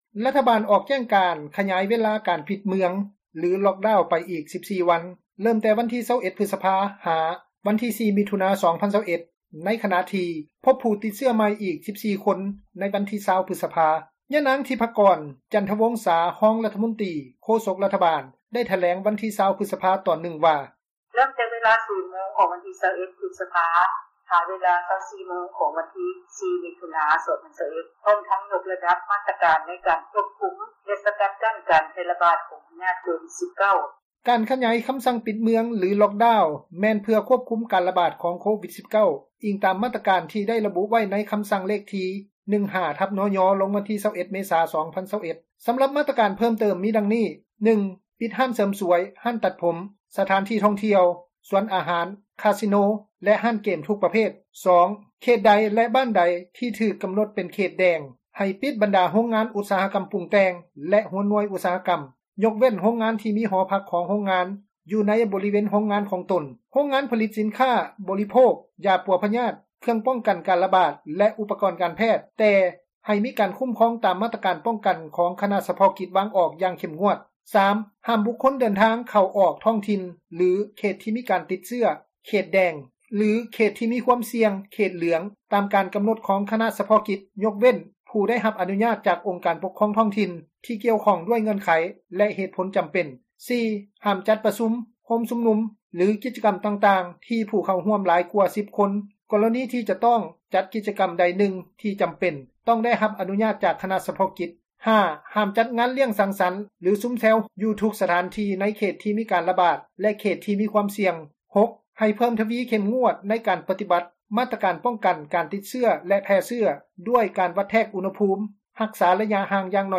ທາງການສັ່ງປິດເມືອງ ຕໍ່ອີກ 14 ມື້ – ຂ່າວລາວ ວິທຍຸເອເຊັຽເສຣີ ພາສາລາວ
ຍານາງ ທິບພະກອນ ຈັນທະວົງສາ ຮອງຣັຖມົນຕຼີ, ໂຄສົກ ຣັຖບານ ໄດ້ຖແລງໃນວັນທີ 20 ພຶສພາ ຕອນນຶ່ງວ່າ: